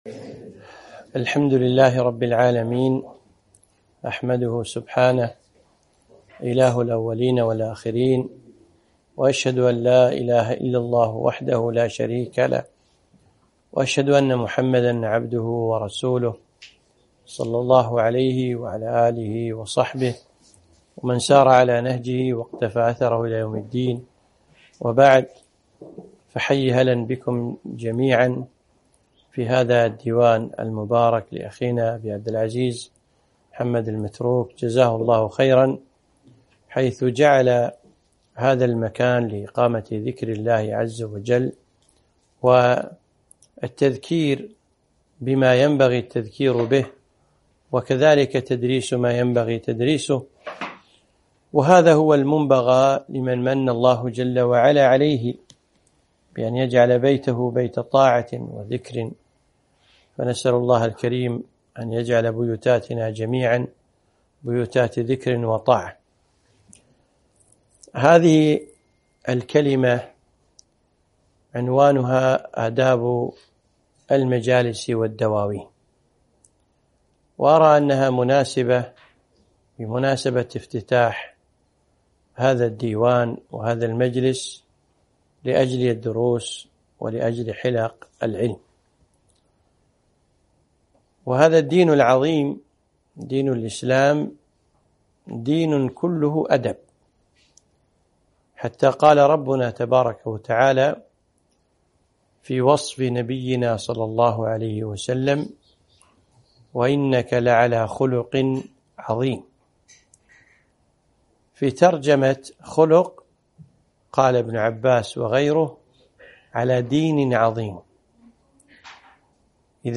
محاضرة - آداب المجالس والدواوين